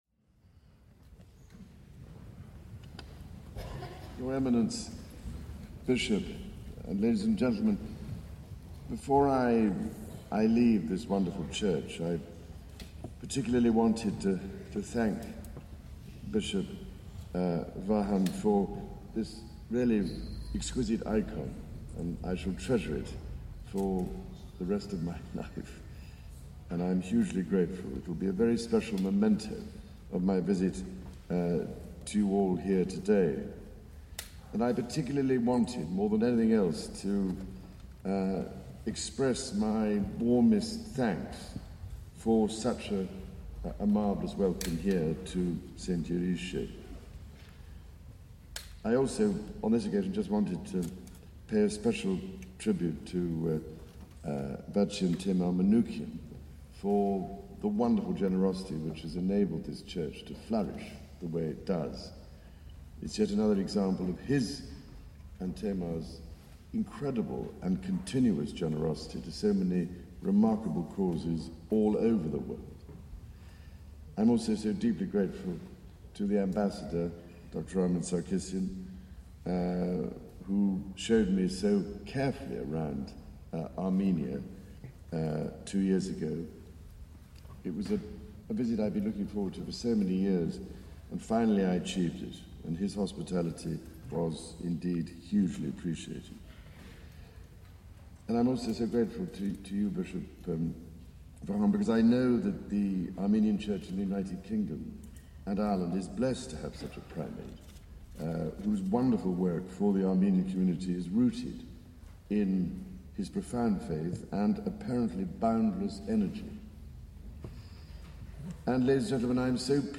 Prince Charles speaking at the St Yeghiche Armenian Church in London